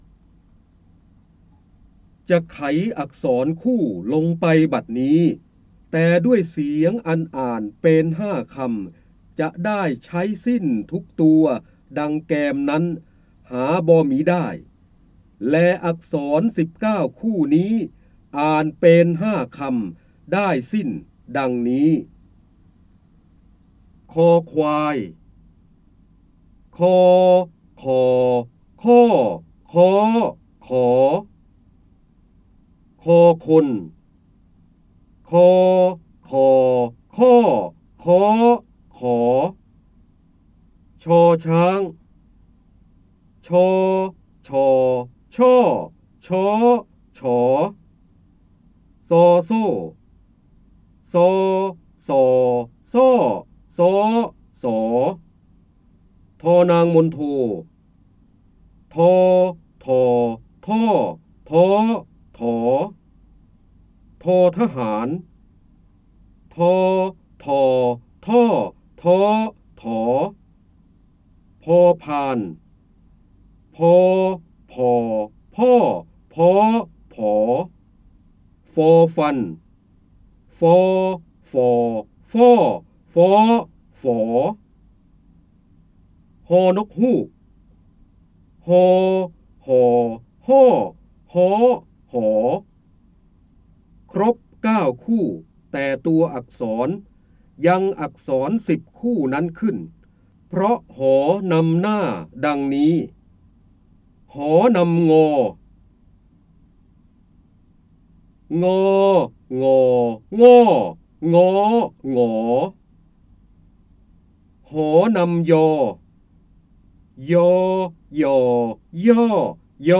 คำสำคัญ : ร้อยแก้ว, พระเจ้าบรมโกศ, พระโหราธิบดี, การอ่านออกเสียง, ร้อยกรอง, จินดามณี